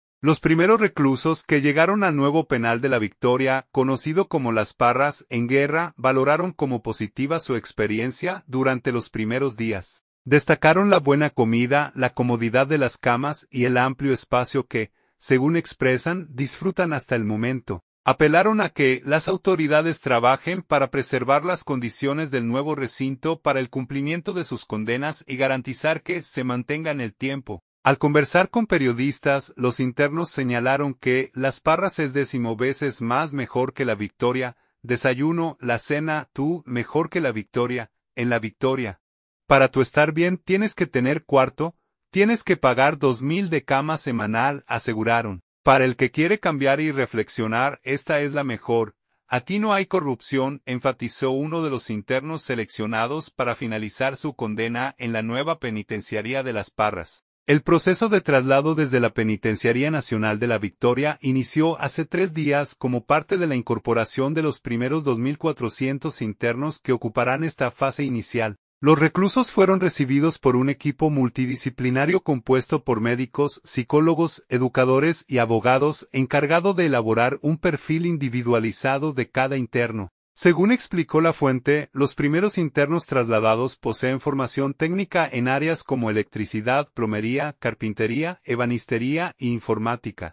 Al conversar con periodistas, los internos señalaron que Las Parras es “10 veces más mejor que La Victoria; desayuno, la cena, to’ mejor que La Victoria. En La Victoria, para tú estar bien tienes que tener cuarto; tienes que pagar 2 mil de cama semanal”, aseguraron.